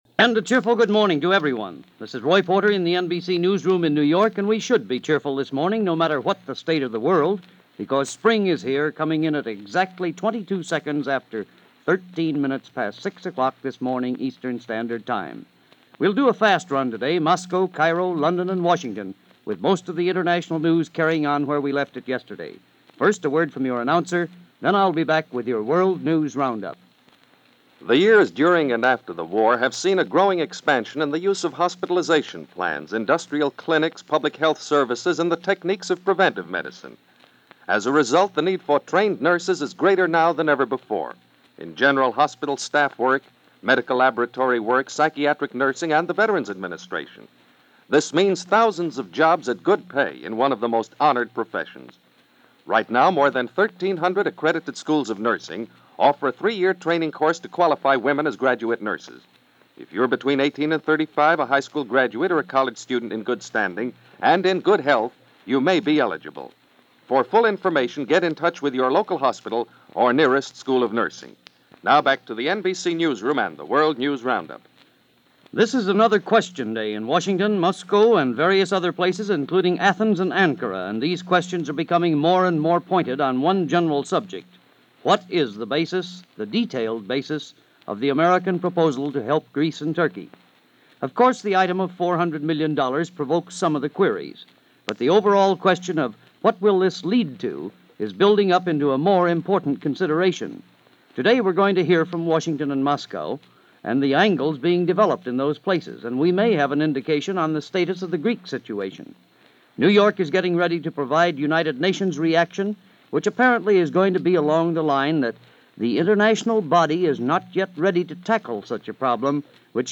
Springtime In Salonika - The Big Four Conference - Aid For Greece - March 21, 1947 - NBC Radio World News Roundup.